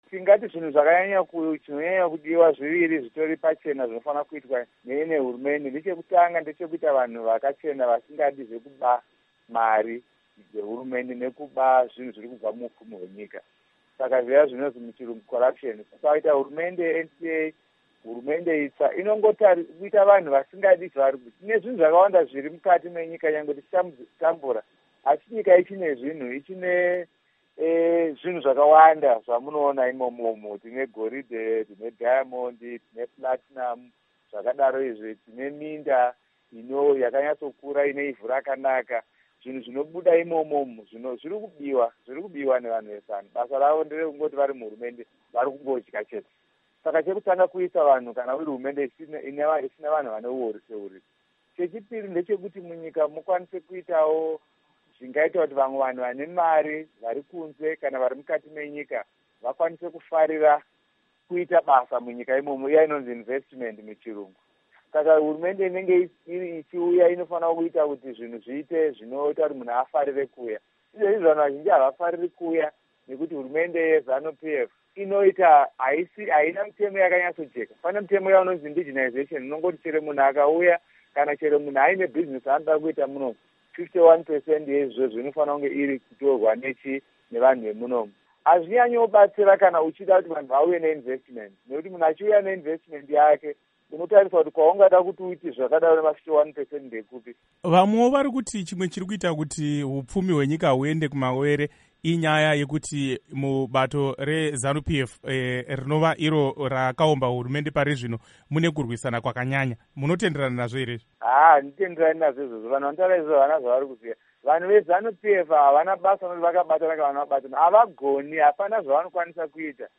Hurukuro naVaLovemore Madhuku